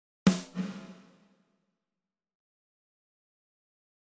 Je fais simple et j'accentue les réglages pour que vous compreniez bien de quoi il s'agit
Ici j'ai baissé les ER et allongé le reverb delay :